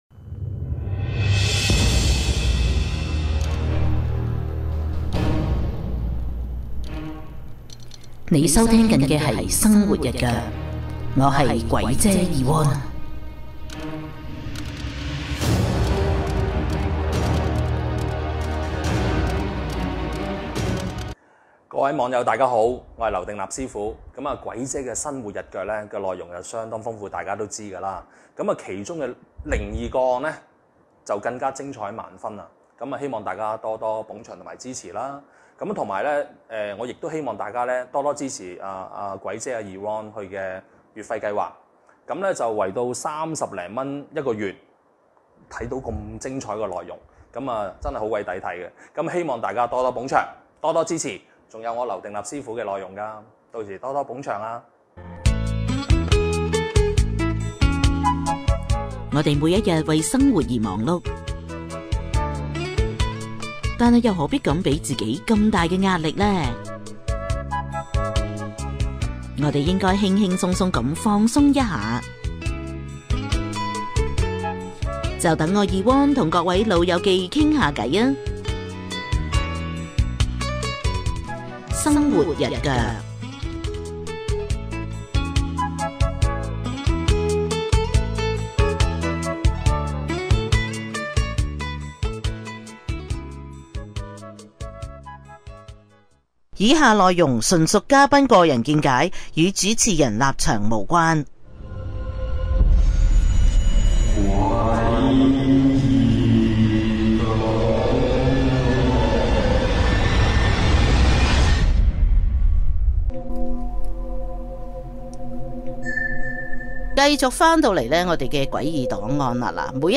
特別訪問